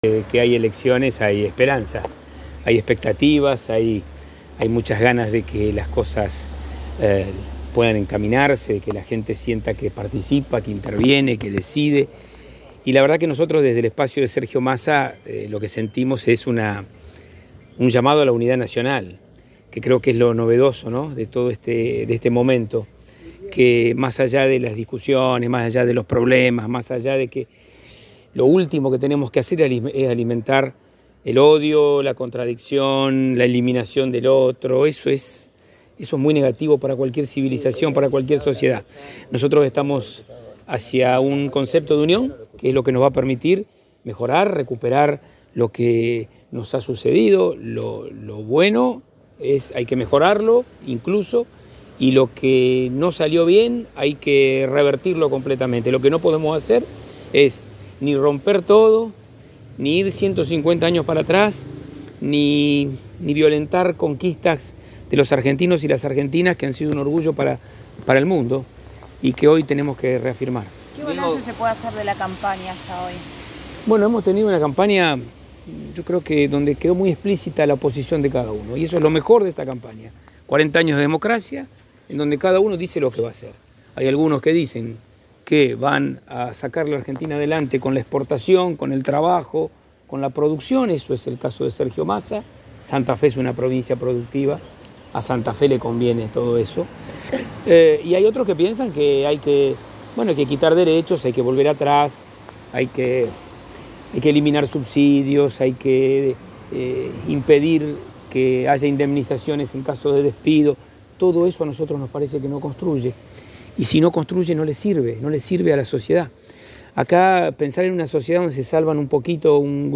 En diálogo con el móvil de Cadena 3 Rosario, afirmó que permanecerá en la ciudad hasta la noche, cuando partirá a Buenos Aires para acompañar en el bunker a Sergio Massa.